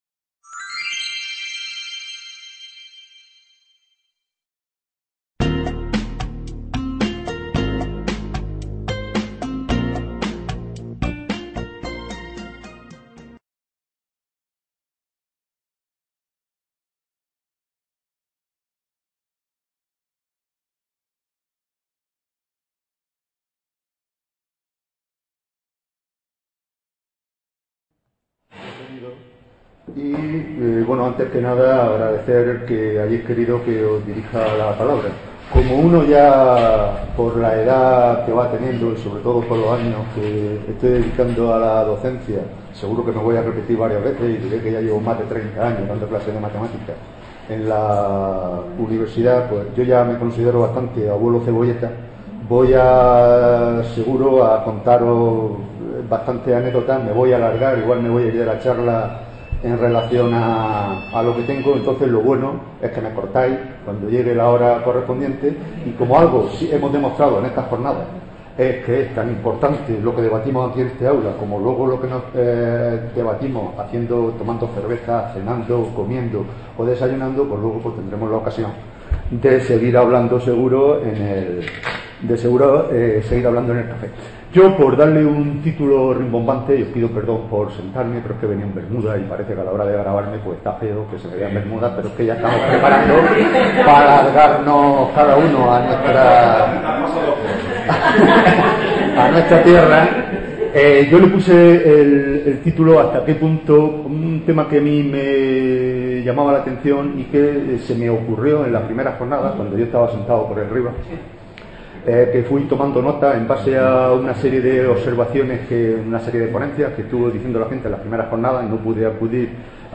imparte esta conferencia dentro de las III Jornadas de Experiencias e Innovación Docente en Estadística y Matemáticas (EXIDO). Estas Jornadas pretenden, como ya lo hiciese en los dos últimos años 2016 y 2017, ser un punto de encuentro para compartir experiencias educativas reales en Estadística y/o Matemáticas que se enmarquen en el ámbito cotidiano de la docencia en los diferentes niveles de la educación Matemática aunque está abierto a docentes de otras materias.